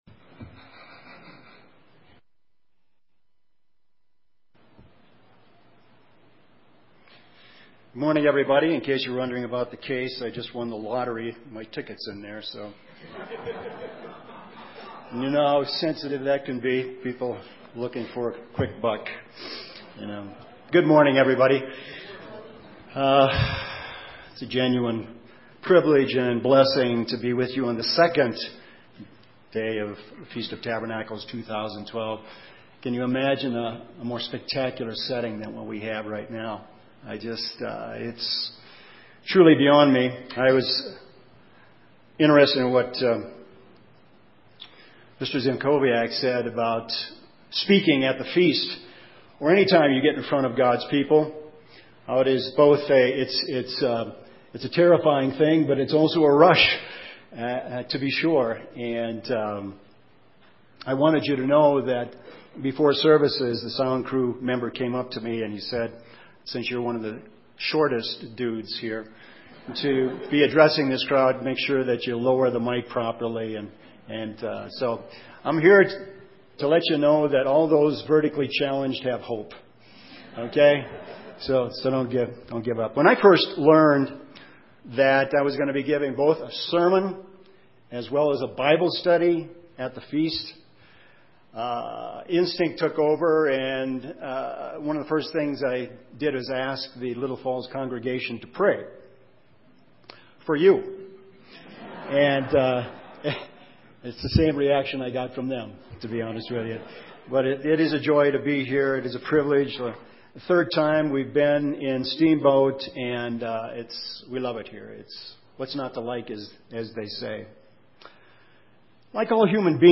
This sermon was given at the Steamboat Springs, Colorado 2012 Feast site.